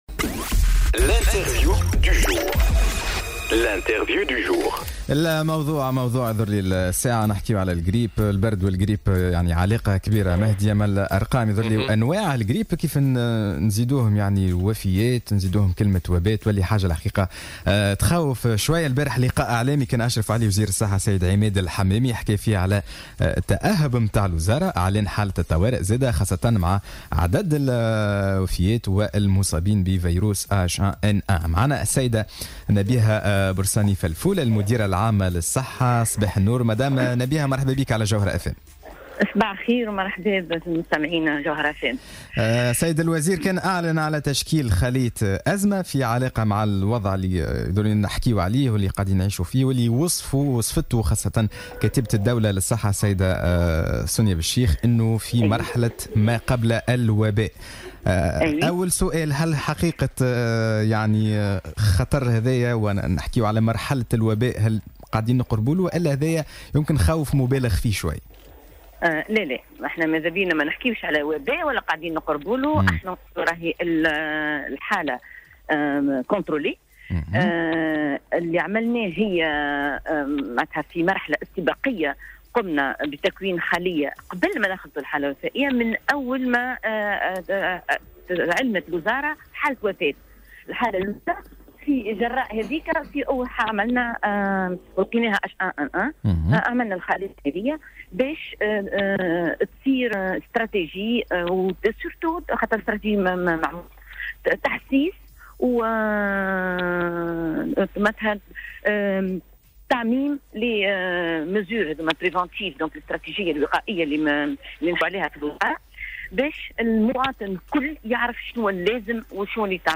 أكدت المديرة العامة للصحة نبيهة البرصالي فلفول في مداخلة لها على الجوهرة اف ام...